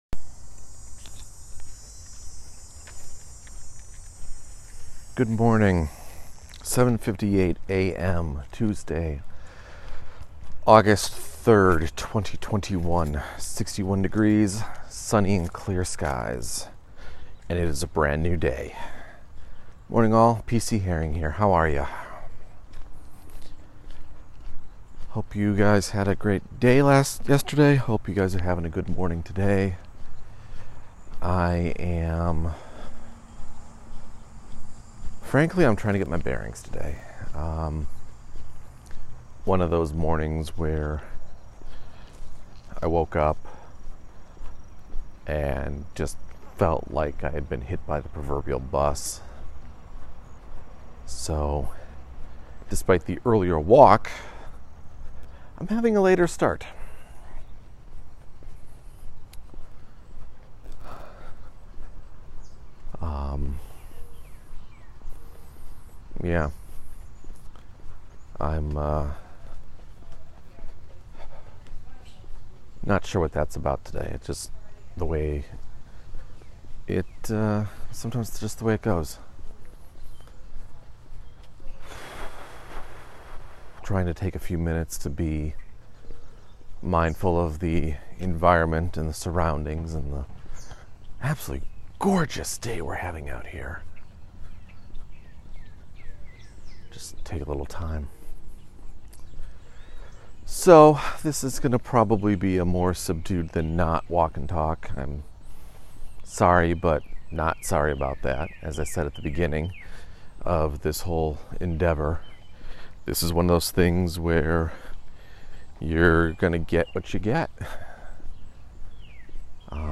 A low key walk today, but I update on writing projects and pimp my social media networks.